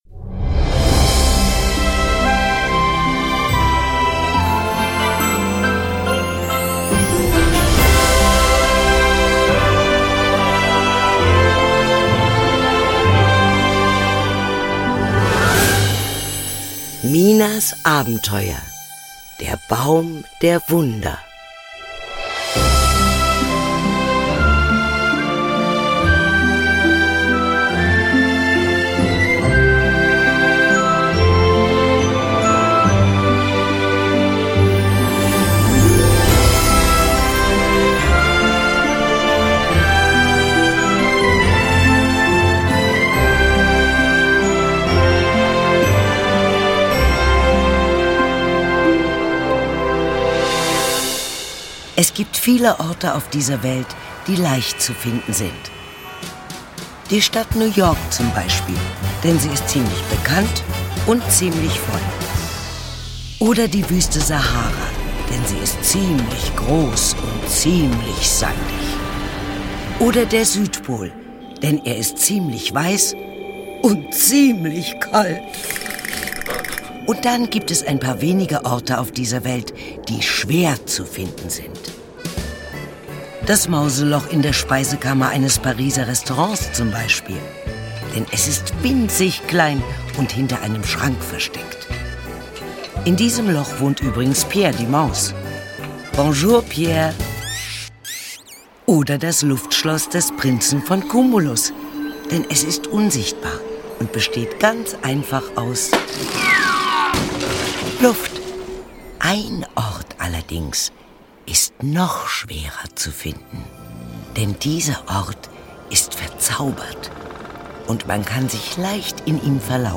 Ravensburger Minas Abenteuer - Der Baum der Wunder ✔ tiptoi® Hörbuch ab 5 Jahren ✔ Jetzt online herunterladen!
Minas_Abenteuer-Hoerprobe.mp3